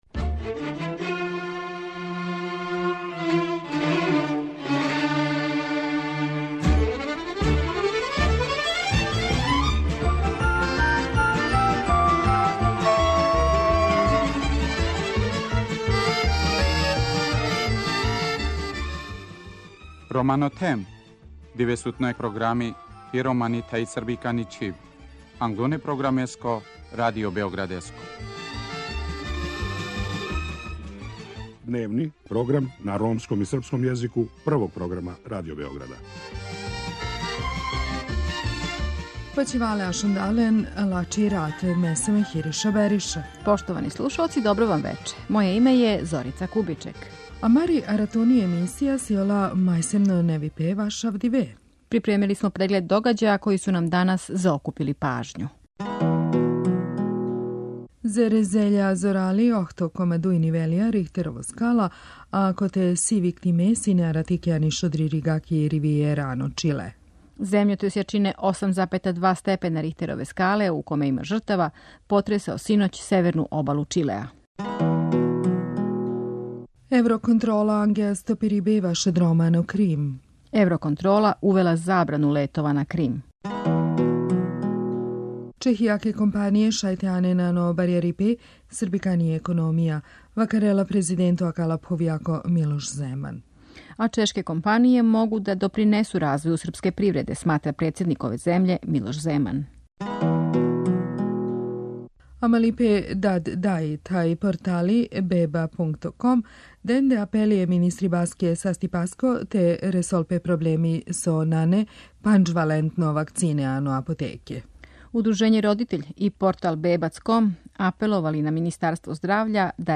У сусрет Међународног дана Рома разговарамо са замеником Заштитника грађана Робертом Сепијем о положају припадника ове националне заједници у Србији.